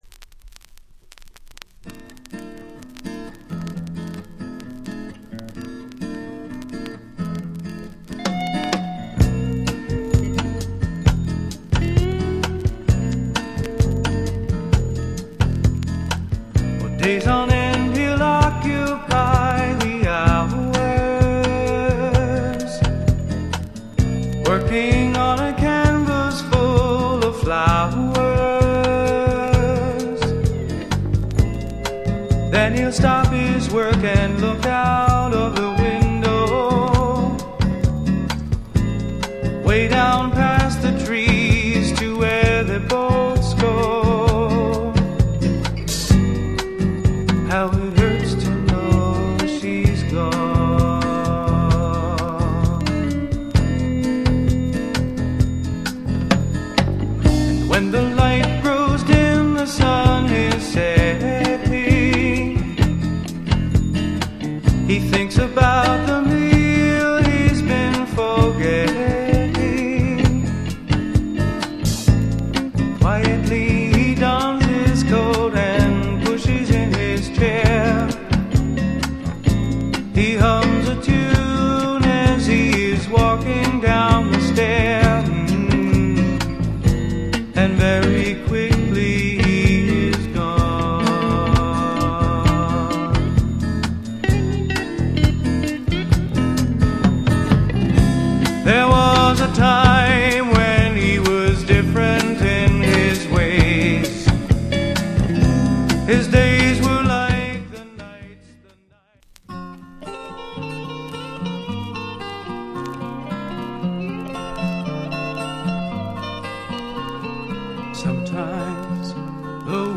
ニュージャージー産ローカル・ジャジー 超極上SSW-FOLK作品。